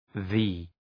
Shkrimi fonetik {ði:}